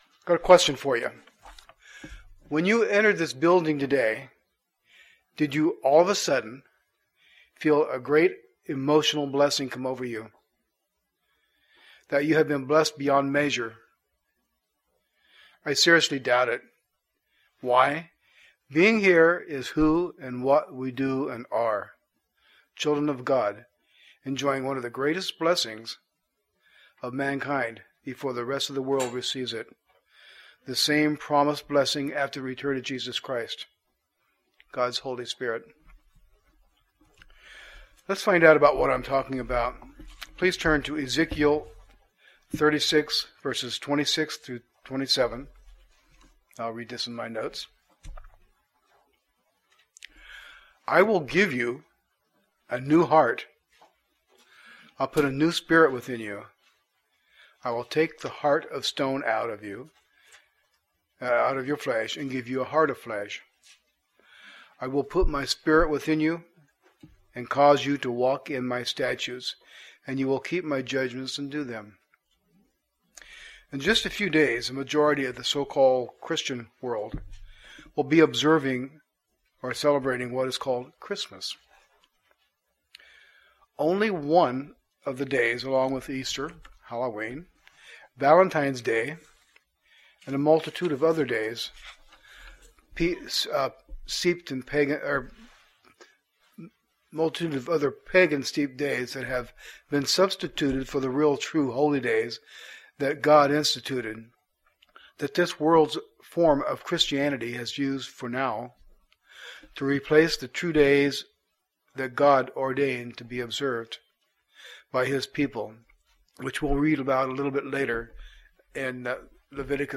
Sermons
Given in Northwest Arkansas